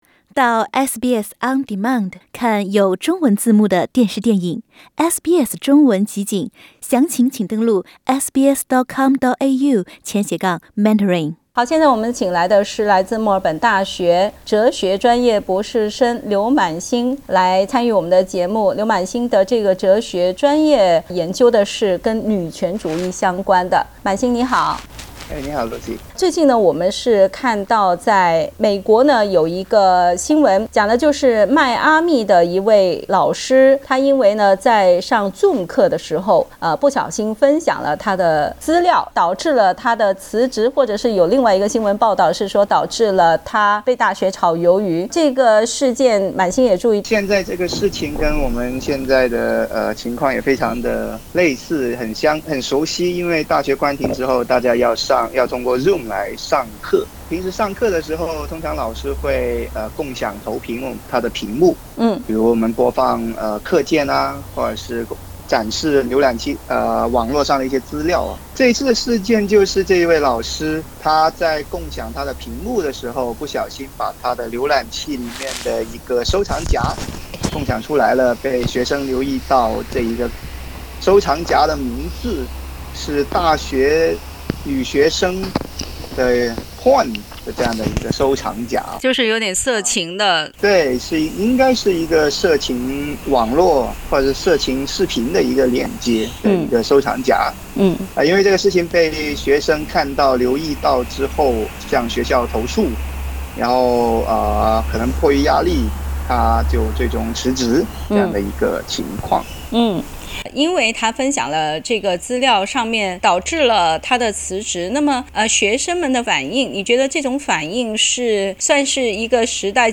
随后，学校称收到学生的投诉，该名教授被炒。点击图片收听详细报道。